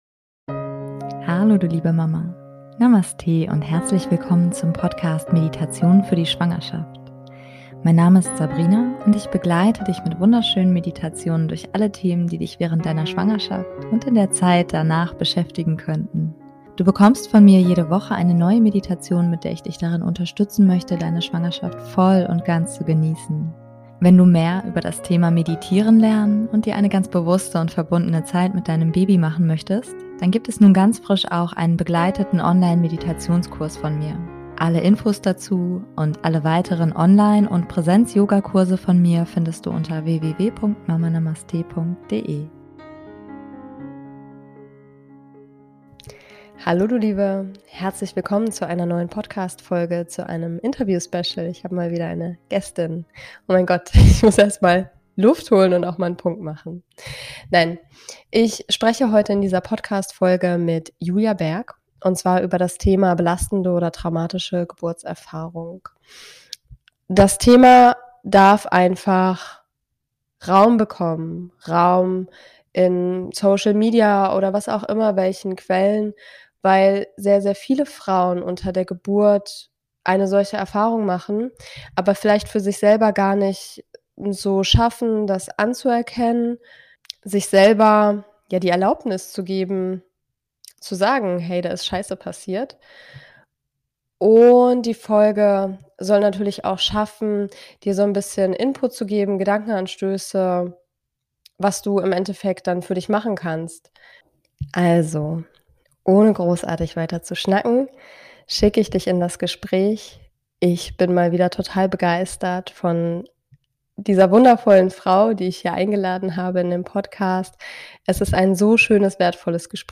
#101 - Negative Geburtserfahrung - Was nun? - Interview